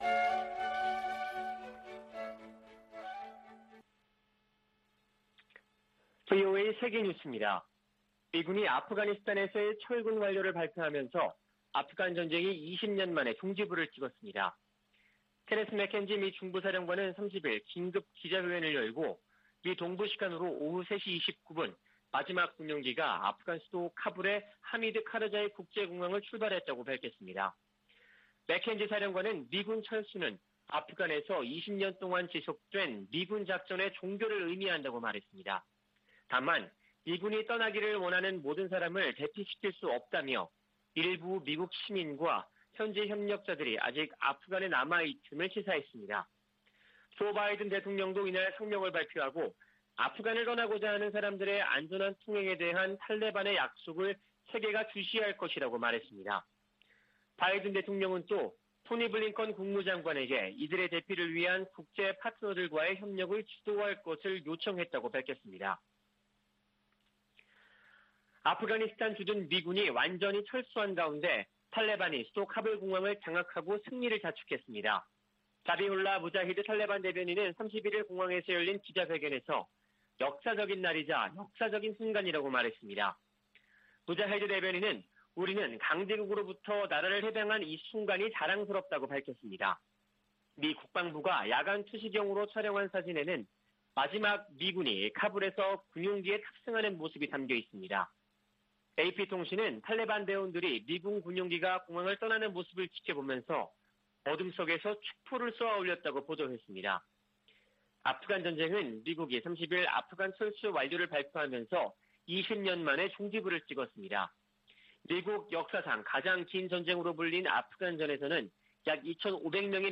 VOA 한국어 아침 뉴스 프로그램 '워싱턴 뉴스 광장' 2021년 9월 1일 방송입니다. 미국 정부는 북한 영변 핵 시설의 원자로 재가동 정황을 포착했다는 국제원자력기구 보고서에 대해 대화와 외교를 강조했습니다. 미국의 전직 핵 협상가들은 북한이 영변 원자로 재가동을 대미 압박과 협상의 지렛대로 이용할 수 있다고 분석했습니다. 미국 하원 군사위원회의 2022회계연도 국방수권법안에 4년 만에 처음으로 주한미군 감축을 제한하는 조항이 포함되지 않았습니다.